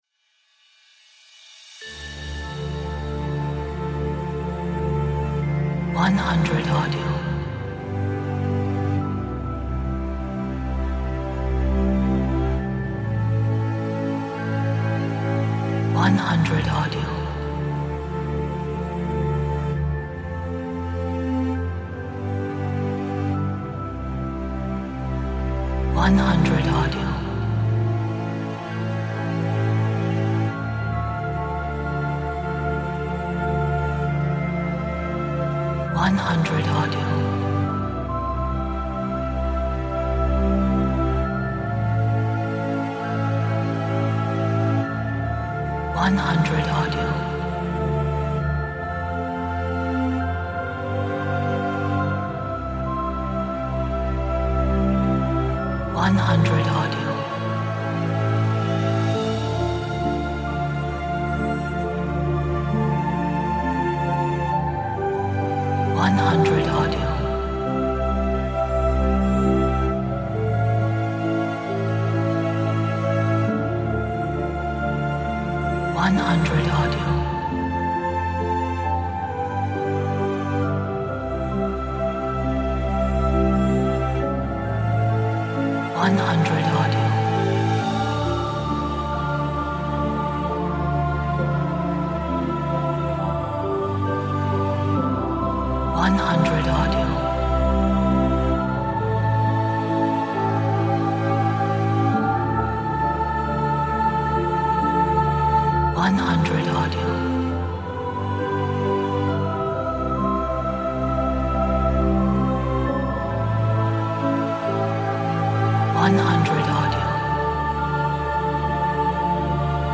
这是一首柔和的电影曲目，拥有缠绵的管弦乐，女性合唱团以及木管乐器。